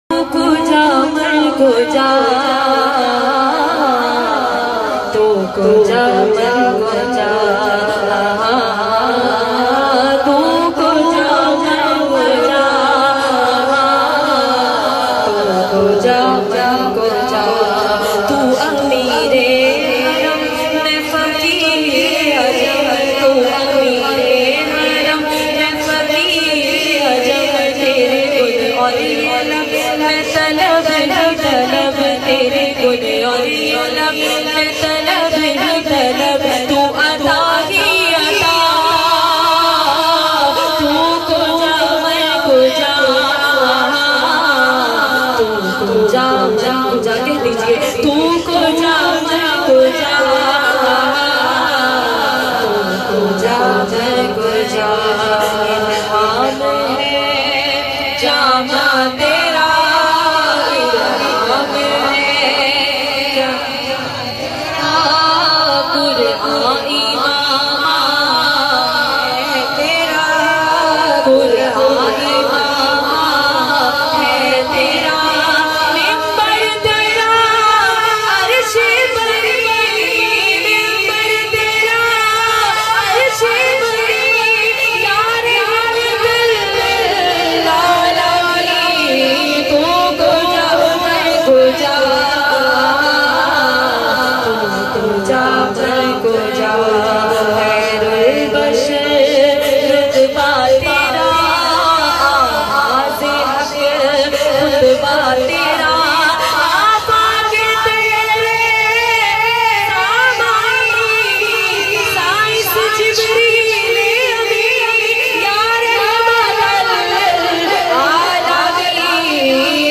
Naat Shareef